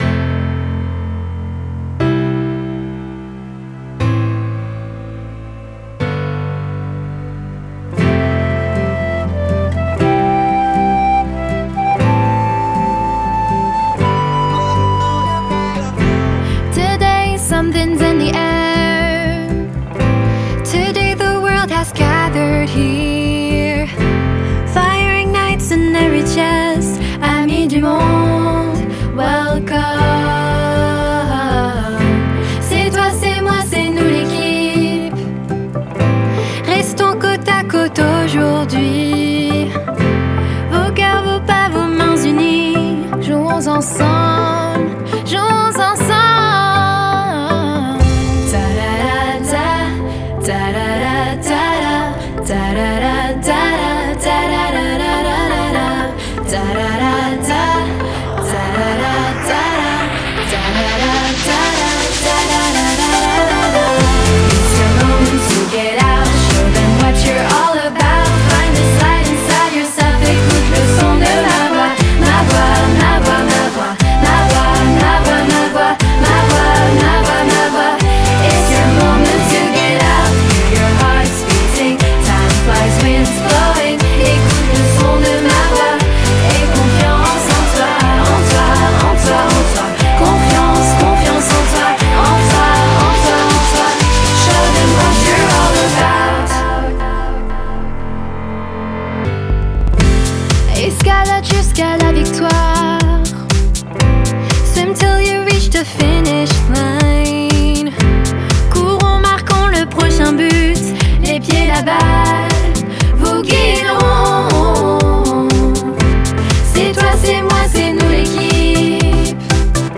L’hymne des Jeux internationaux de la Jeunesse est né à Singapour en 2017. Il a été crée par la chorale du Lycée Français de Singapour.